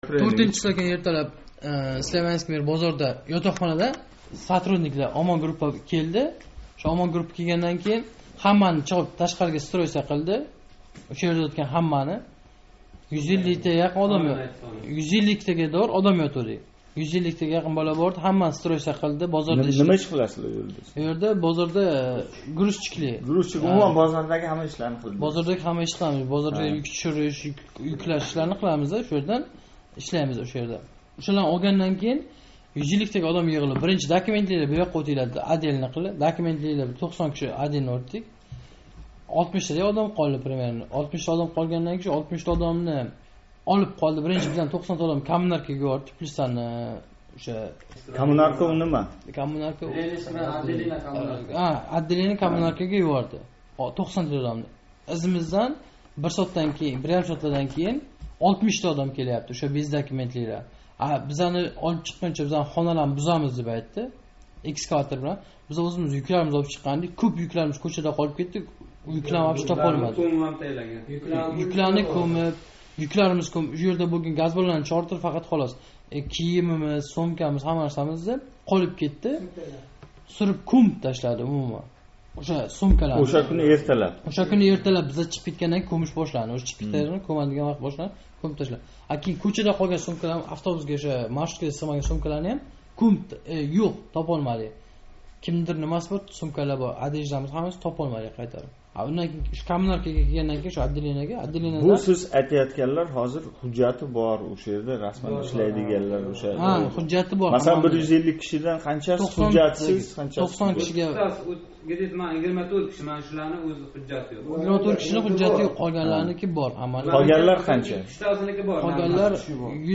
Яқинлари ушлаб кетилган меҳнат муҳожирлари билан суҳбат